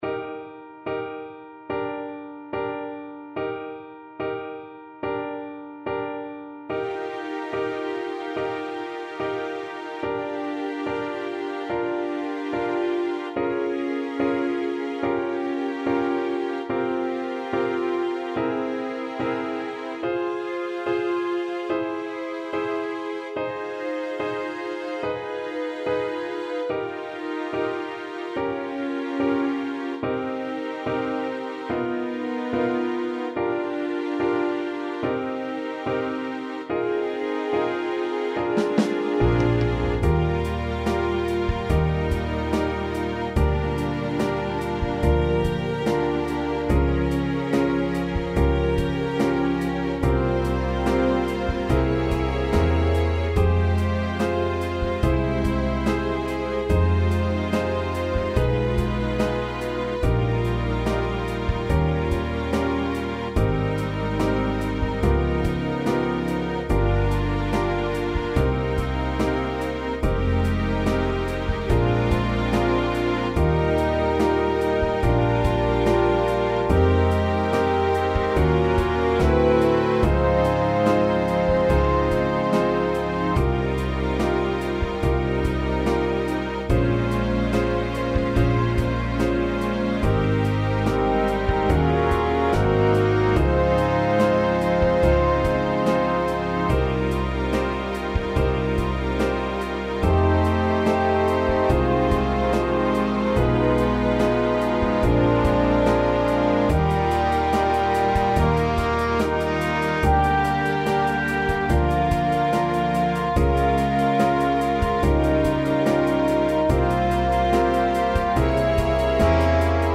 Strings Sheet Music for Recording Session
Recording Session 3/14/19 at UIUC Recording Studio